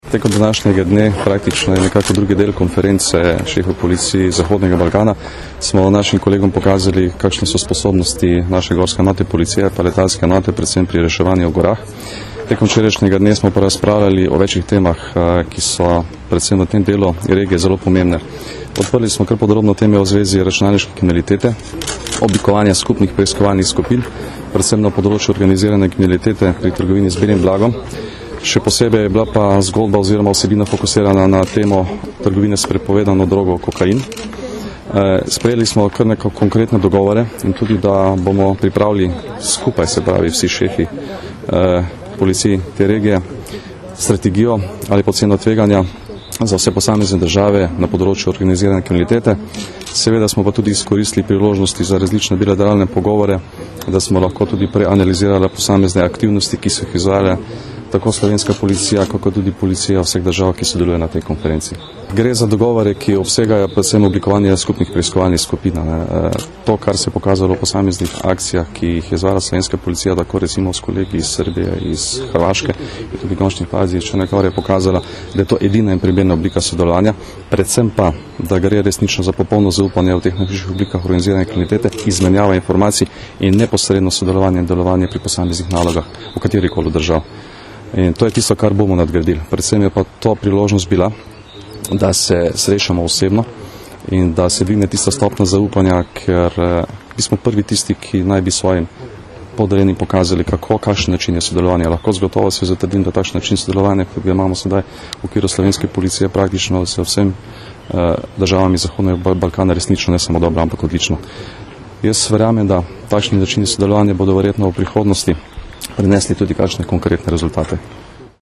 Zvočni posnetek izjave generalnega direktorja policije Janka Gorška ob izteku srečanja s kolegi iz tujine